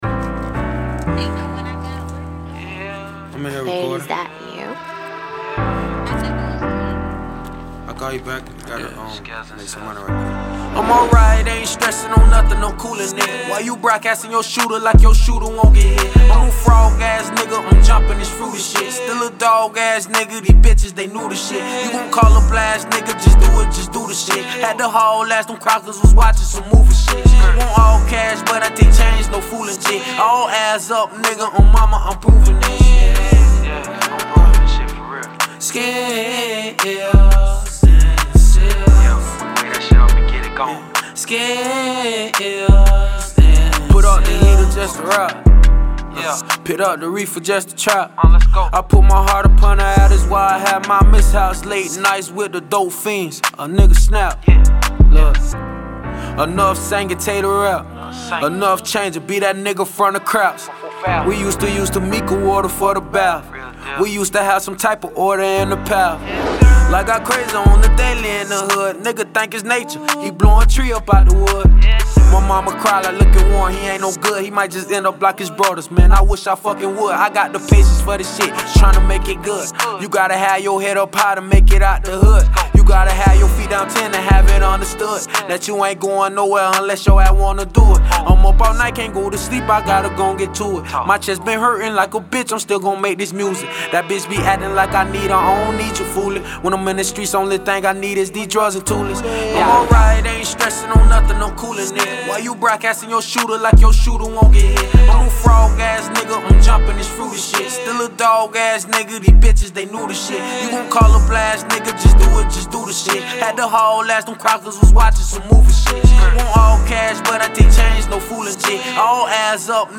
Hiphop
Get ready for a chill vibe